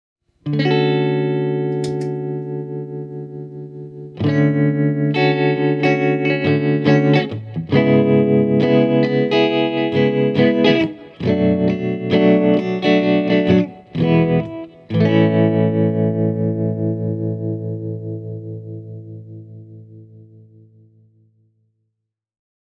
Bluetonen tremolo on hyvin maukas tapaus:
bluetone-princeton-reverb-e28093-telecaster-tremolo.mp3